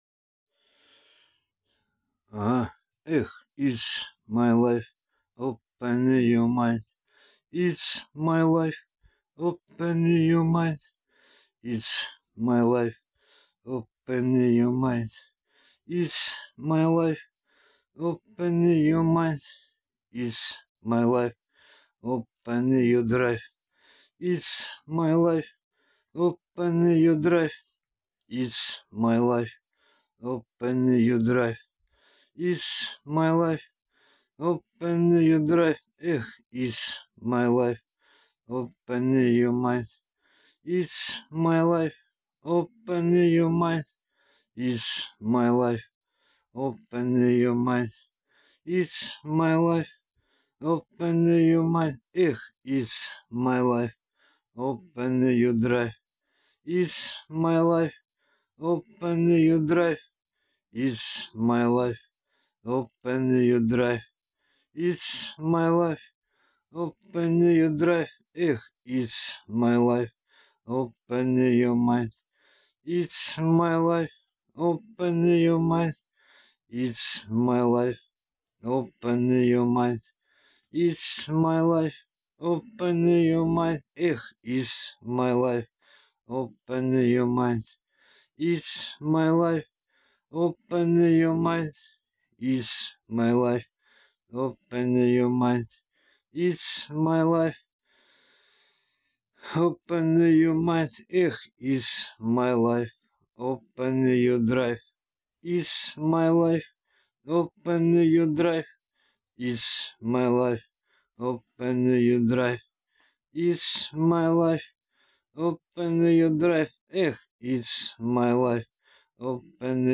А , может быть , что опознается или же распознается хотя бы эта звуковая дорожка, композиция или же мелодия ? . . - С радиостанции - 2000 ? . .